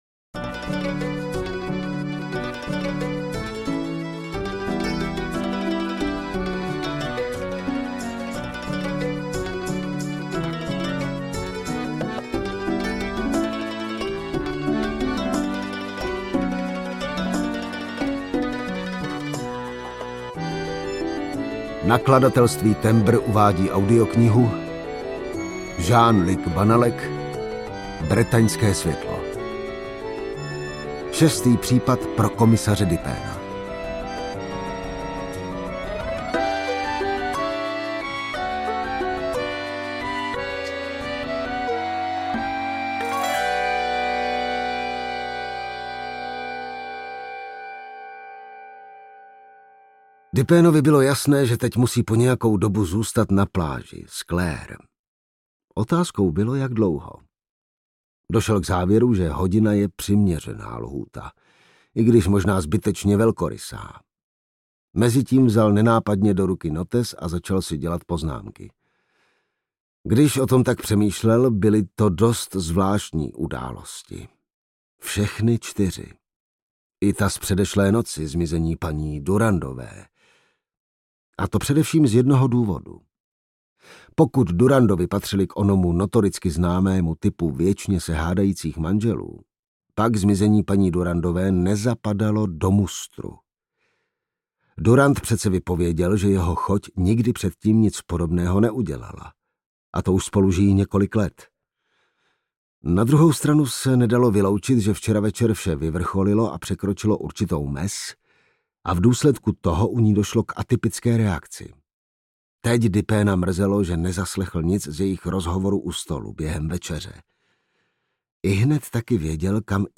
Bretaňské světlo audiokniha
Ukázka z knihy